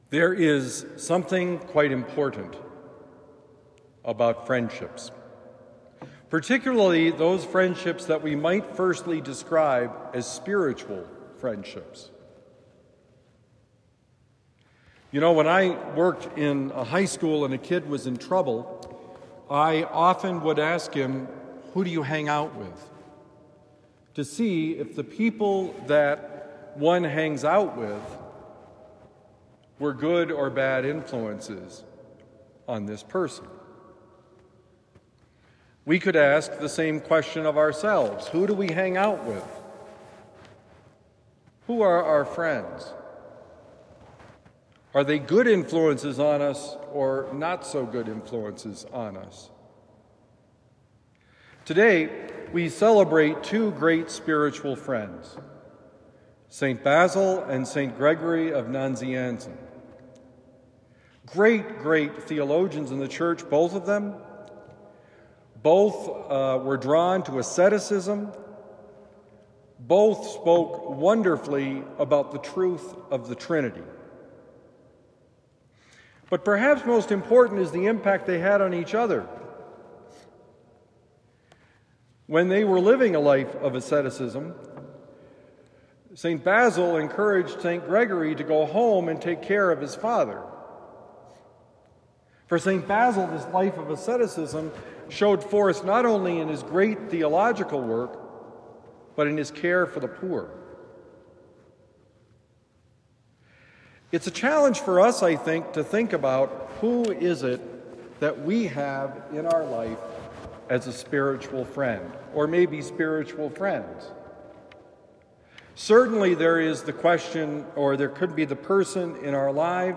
The Importance of Spiritual Friendship: Homily for Thursday, January 2, 2025